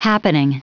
Prononciation du mot happening en anglais (fichier audio)
Prononciation du mot : happening